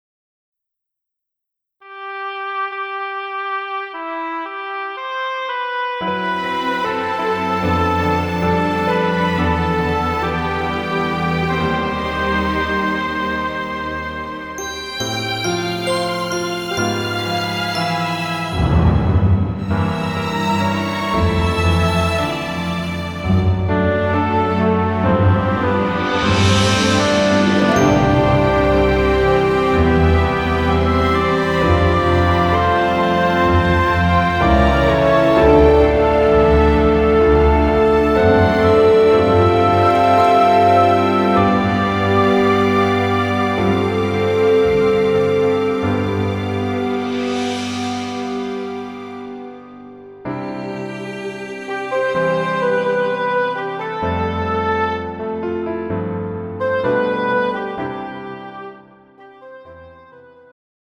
음정 -1키
장르 축가 구분 Pro MR